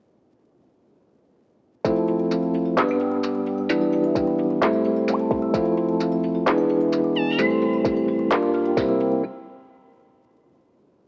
伴奏データ ※右クリックしてファイルをパソコンに直接ダウンロードしてください。